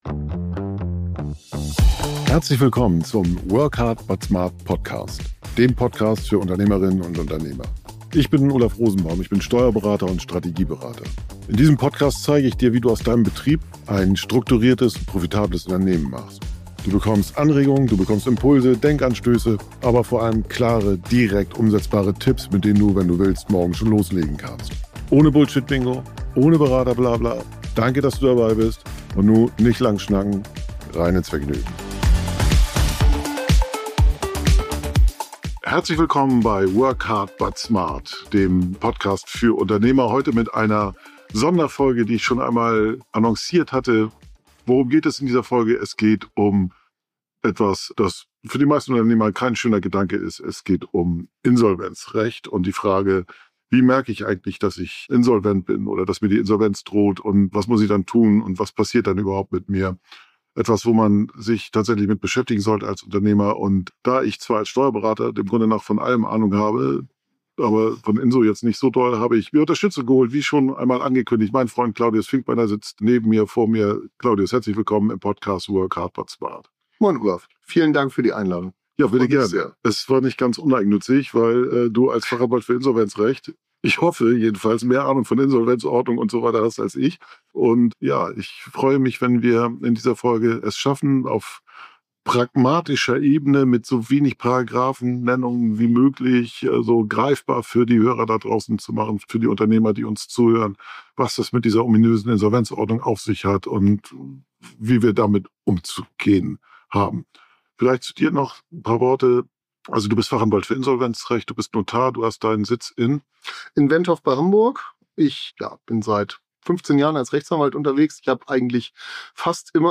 In dieser Folge spreche ich mit dem Fachanwalt für Insolvenzrecht und Notar